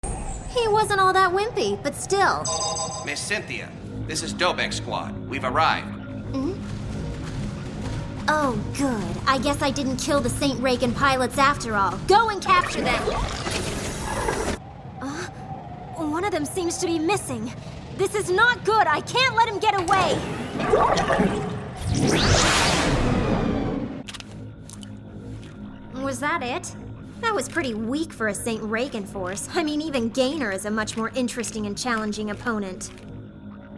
今年気に入ったアニメ吹替
吹替えには、お気に入りのBang Zoom! Entertainment がからんでいます。